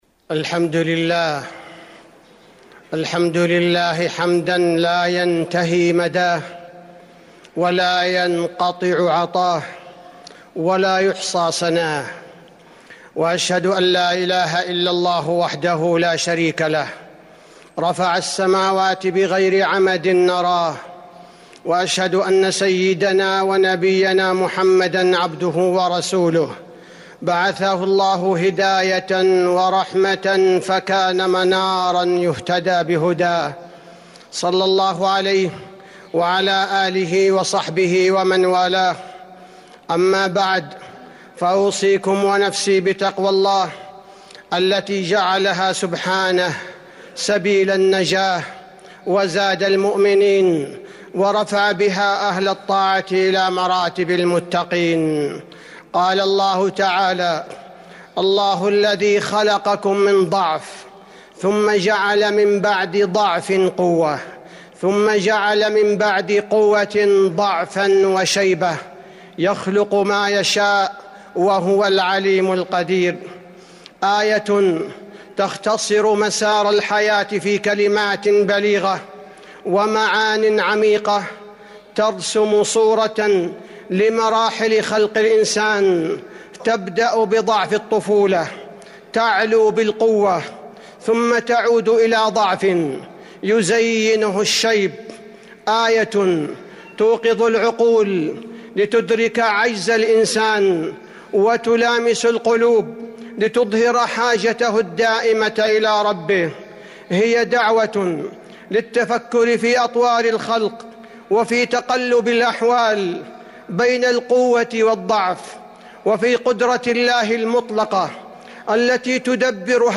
المدينة: ضعف ثم قوة ثم ضعف في الخلق - عبد الباري بن عواض الثبيتي (صوت - جودة عالية